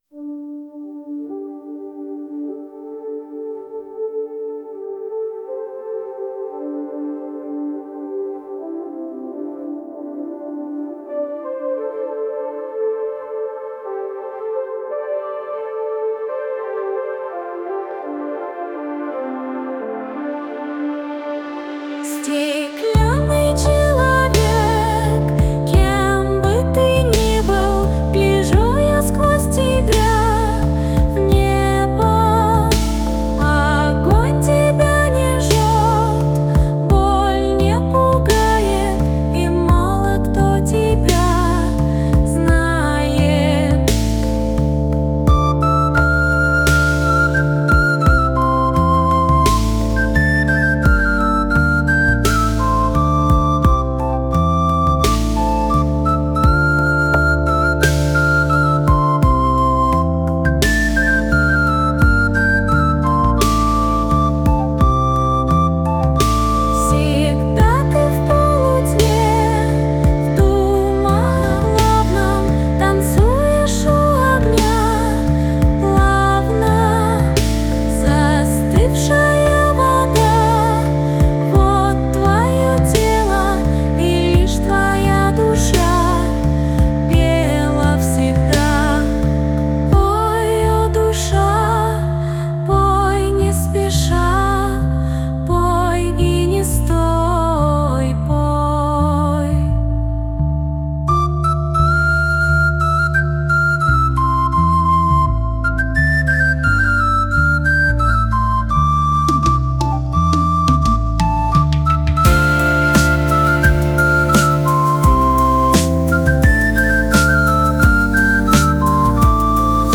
• Жанр: AI Generated